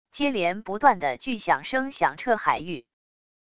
接连不断的据响声响彻海域wav音频